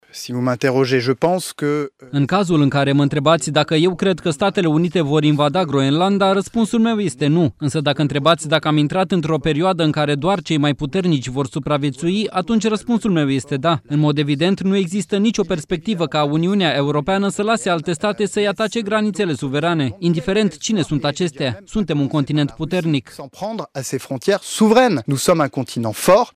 08ian-15-Barrot-despre-Groenlanda-tradus.mp3